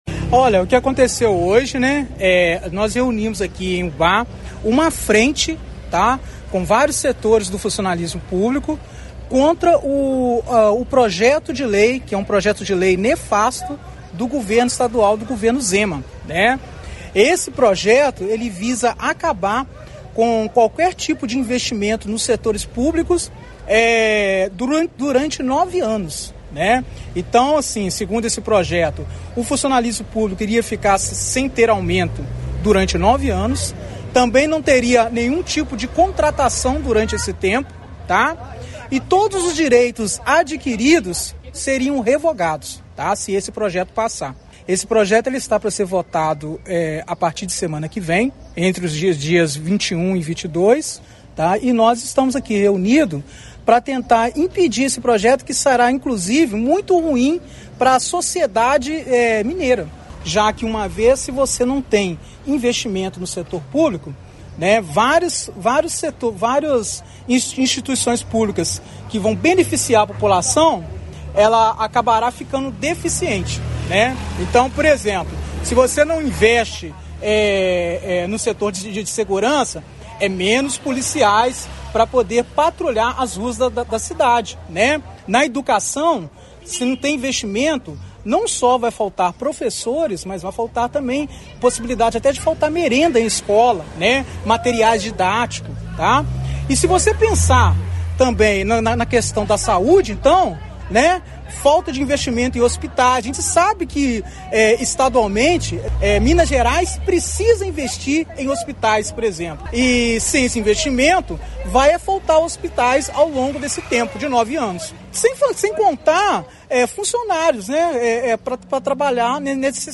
A FRENTE MINEIRA EM DEFESA DO SERVIÇO PÚBLICO (FMDSP) realizou um ato de manifestação na manhã desta sexta-feira, 17 de novembro, em frente à Gerência Regional de Saúde de Ubá.